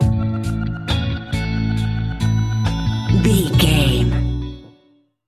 Ionian/Major
laid back
Lounge
sparse
new age
chilled electronica
ambient
atmospheric
morphing